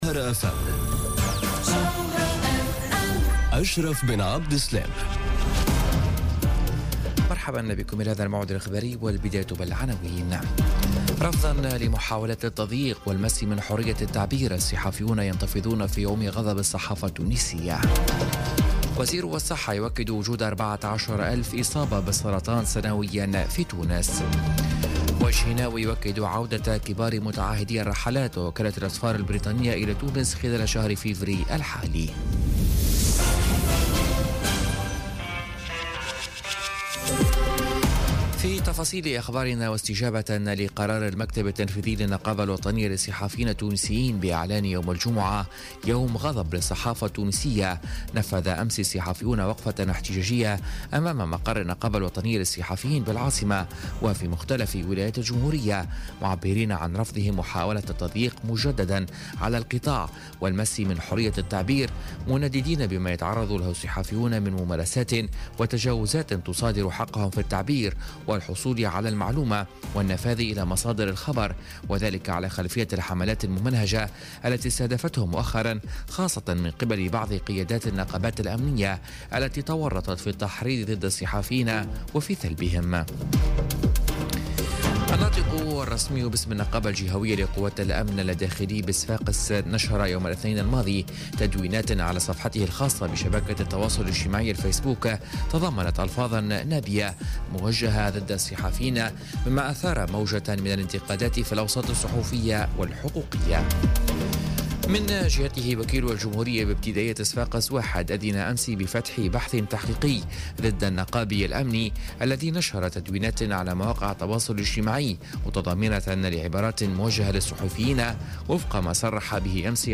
نشرة أخبار منتصف الليل ليوم السبت 3 فيفري 2018